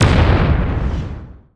Explosion and footstep SFX
AnotherExplosion.wav